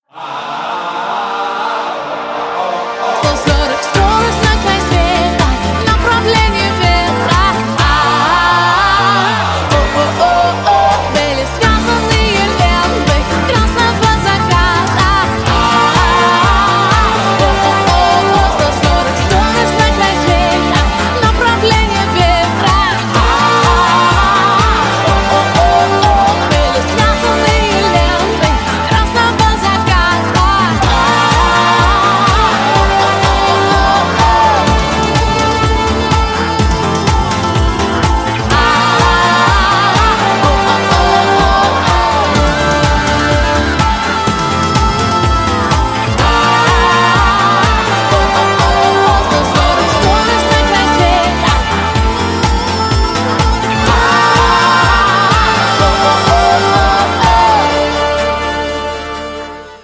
заводные